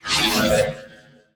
ui_menu_scan_02.wav